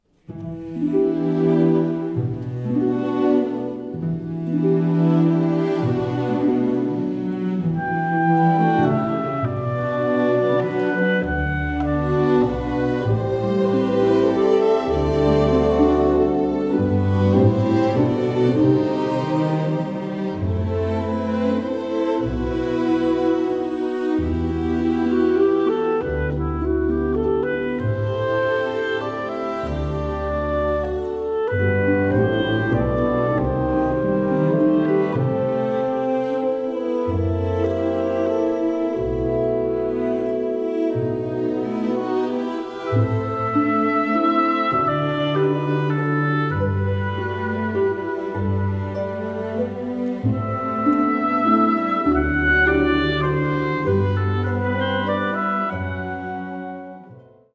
like a romantic and nostalgic musical journey.